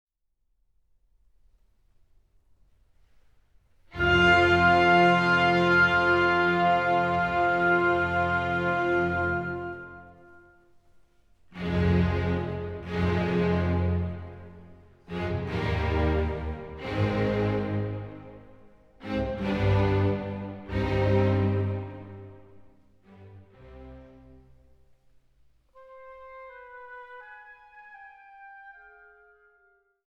Oboe
Trompete
Ouvertüre